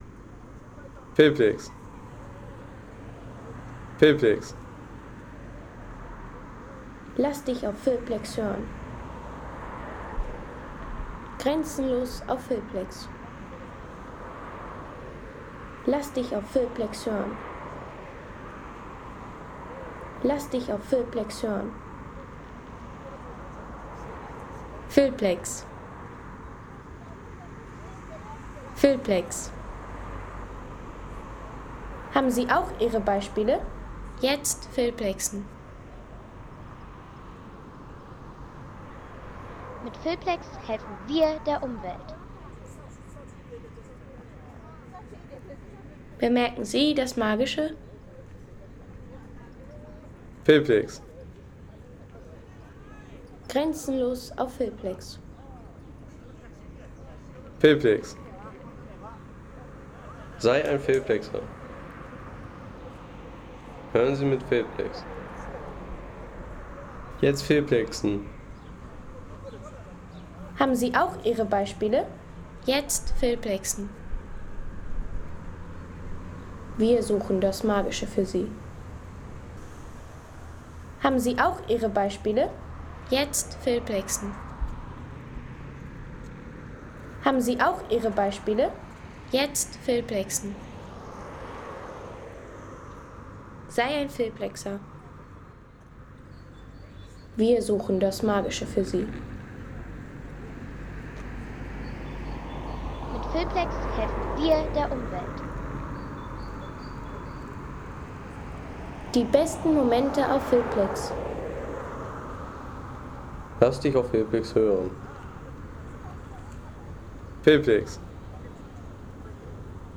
Stadtzentrum auf der Brühlschen Terrasse
Kategorien: Landschaft - Städte